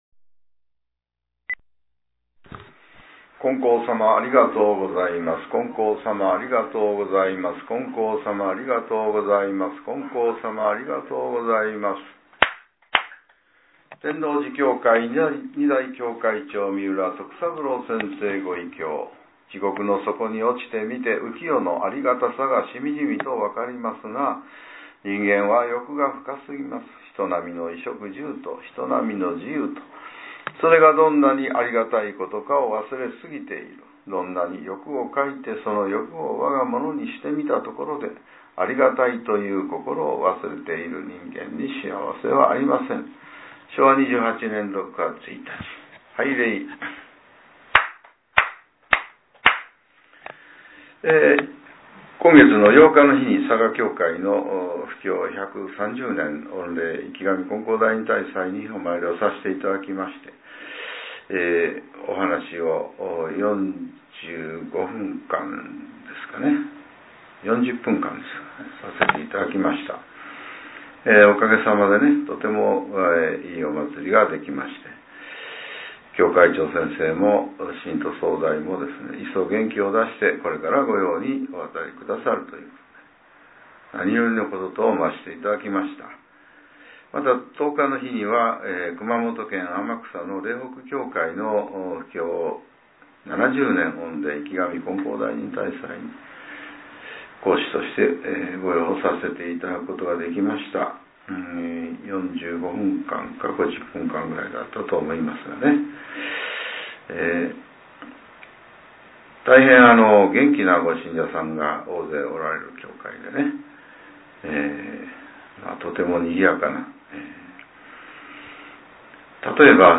令和６年１１月１３日（朝）のお話が、音声ブログとして更新されています。